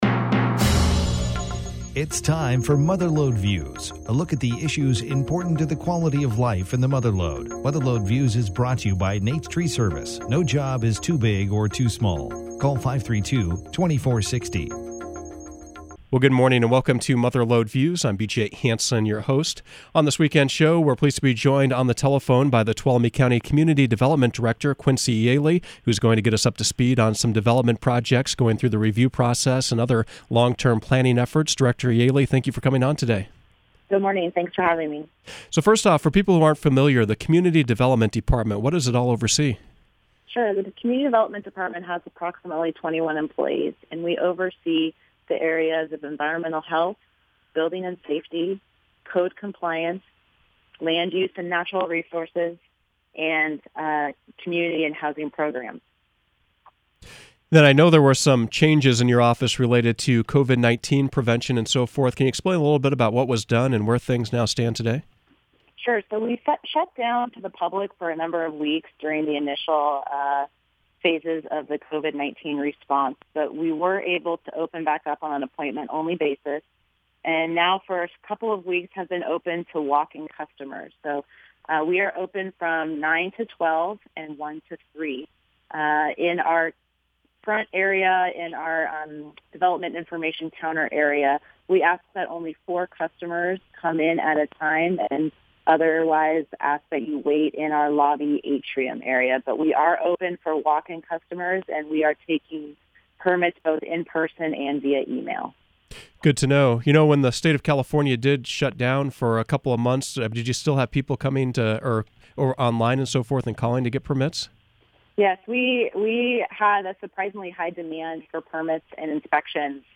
CDD Director Yaley Speaks About Projects
Mother Lode Views featured Tuolumne County Community Development Director Quincy Yaley.